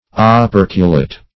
Operculate \O*per"cu*late\, Operculated \O*per"cu*la`ted\, a.